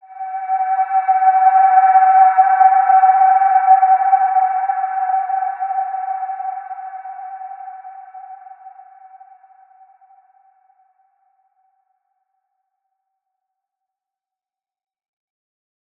Large-Space-G5-mf.wav